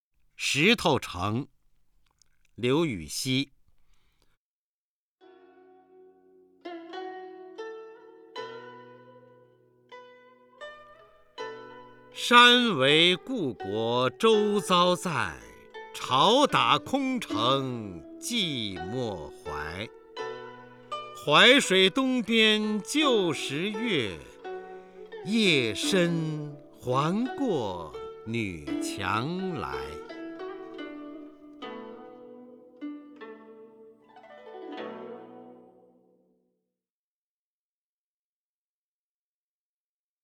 方明朗诵：《石头城》(（唐）刘禹锡) （唐）刘禹锡 名家朗诵欣赏方明 语文PLUS